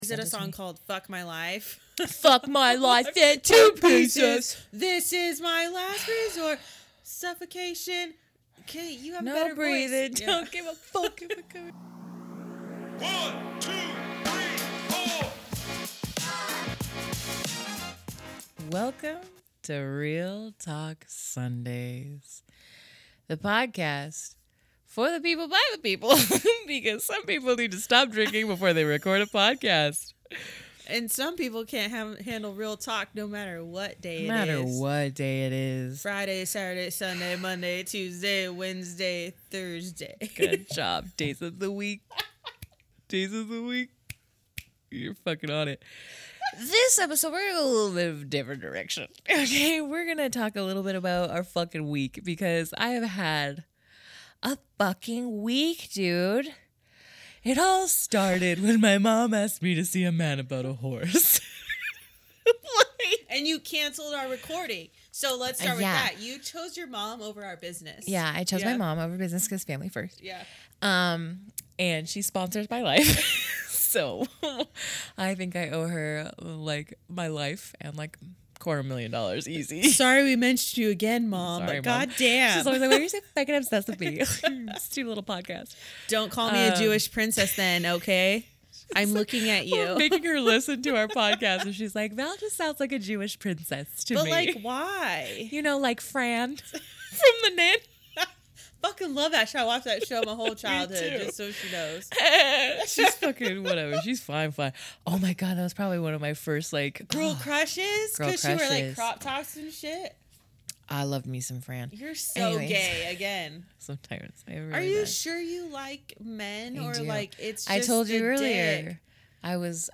As always, come enjoy the witty banter and drunken antics!